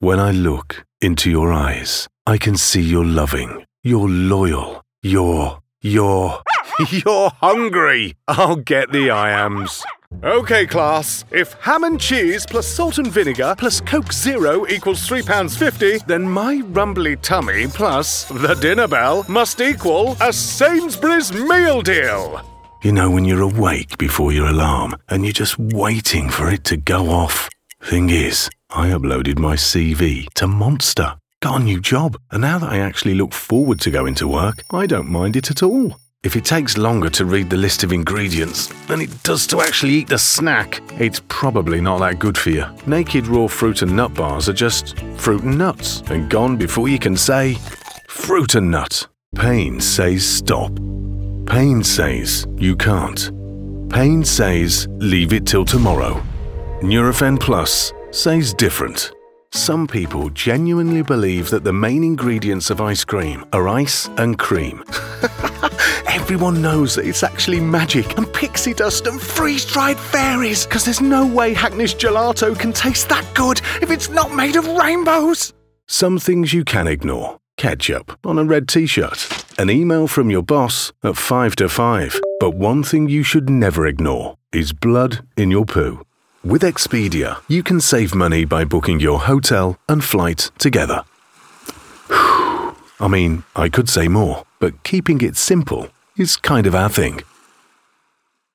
English (British)
Deep, Reliable, Mature, Friendly, Warm
Commercial